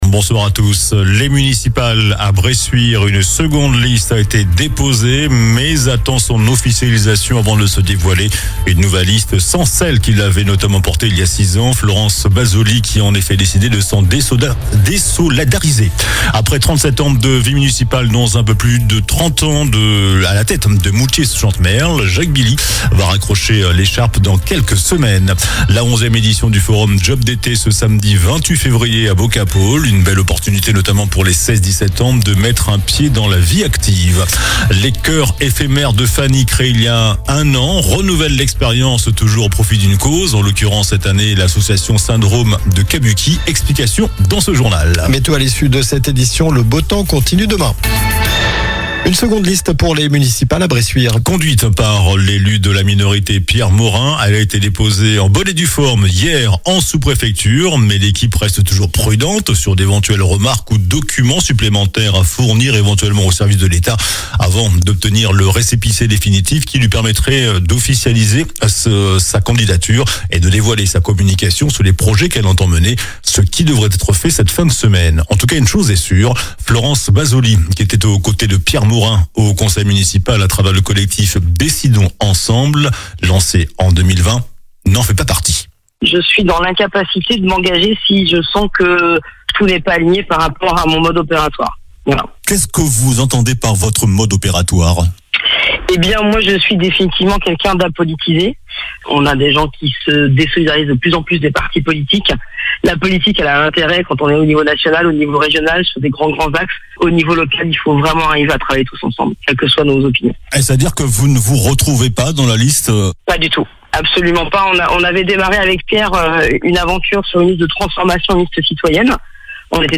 JOURNAL DU MARDI 24 FEVRIER ( SOIR )